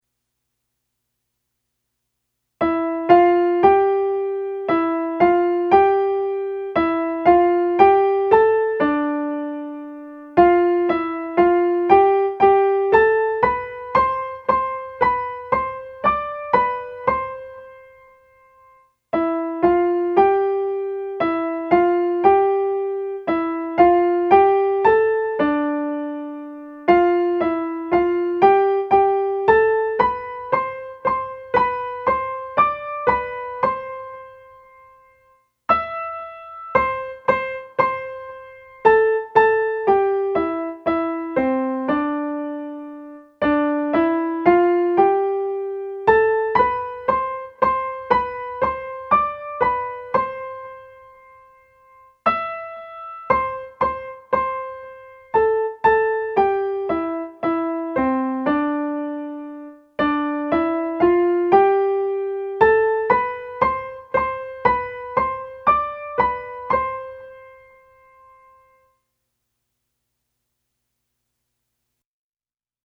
for easy playing by little hands
plus 18 more classic cowboy tunes.